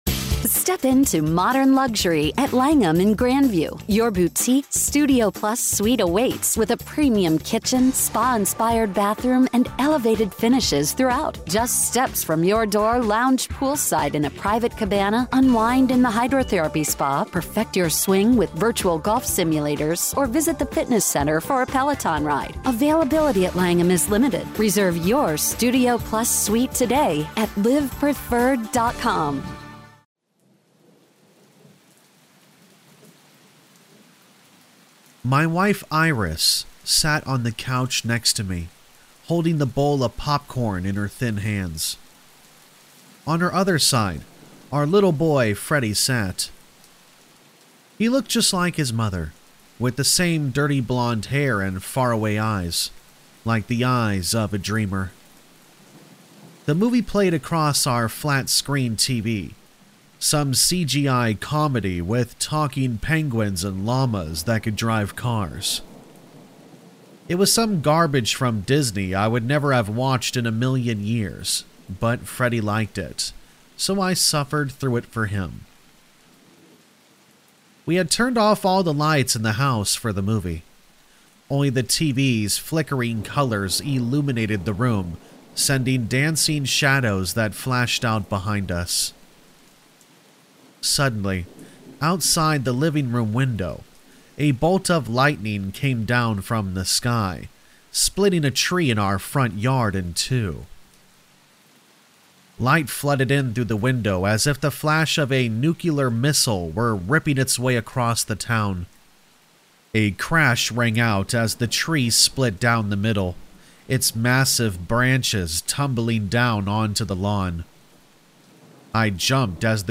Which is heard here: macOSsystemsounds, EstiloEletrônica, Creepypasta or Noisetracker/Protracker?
Creepypasta